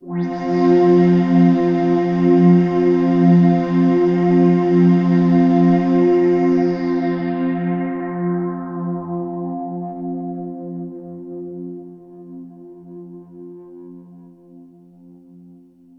Here’s a test with the same 3 sounds on both hardware and software, all recorded into Digitakt II.